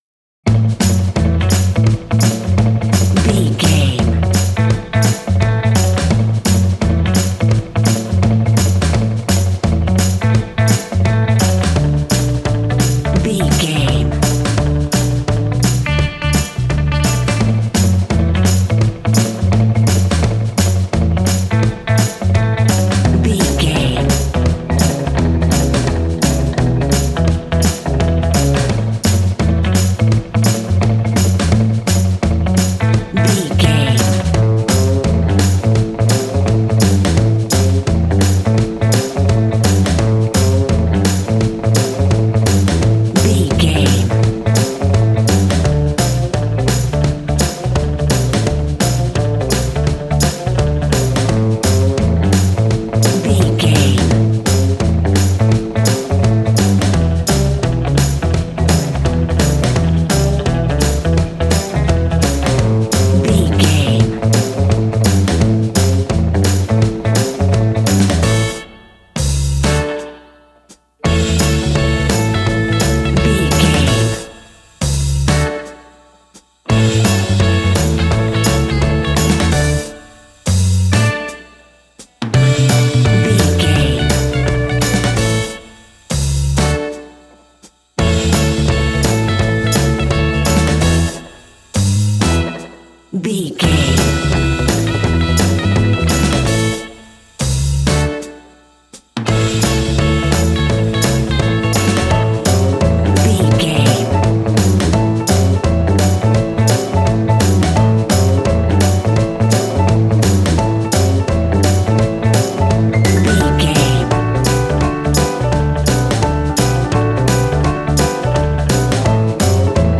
Ionian/Major
cool
uplifting
bass guitar
electric guitar
drums
cheerful/happy